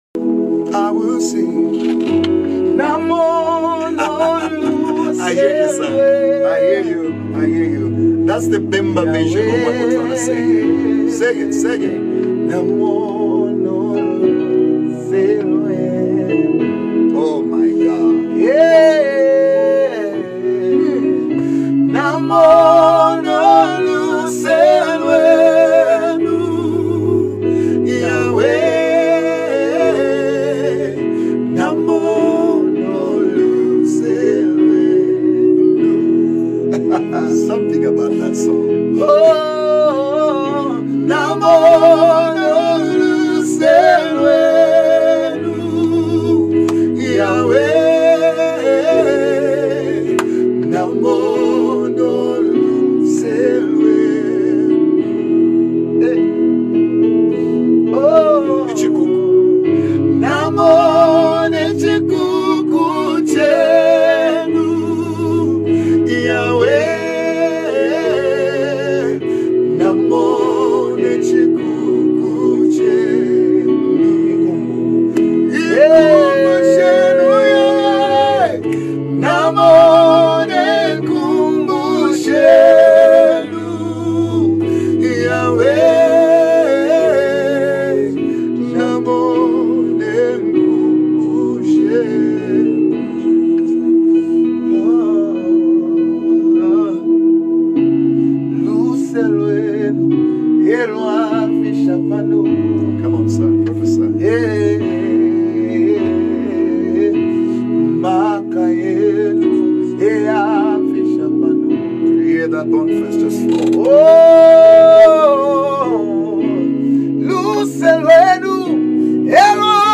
Zambian Worship Songs
The passionate vocals